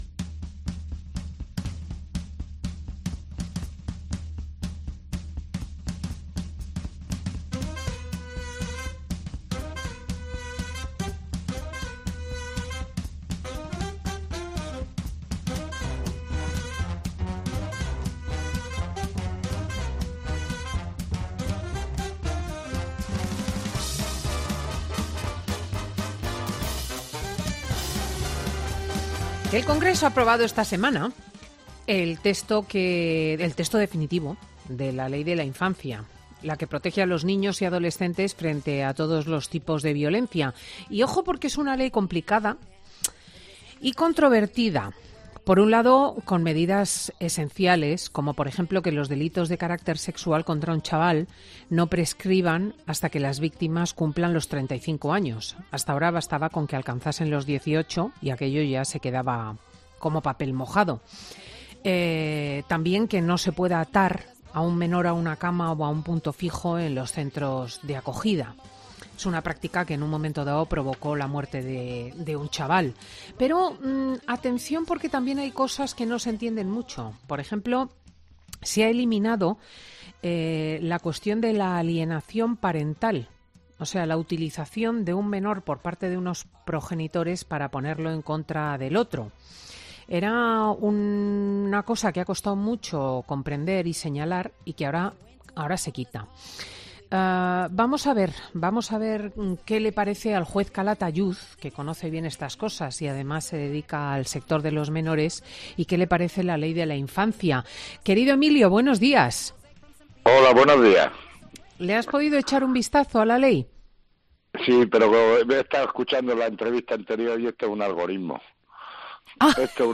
Hablamos con el juez de menores, Emilio Calatayud, sobre las claves de la nueva Ley Orgánica de Protección Integral de la Infancia y la...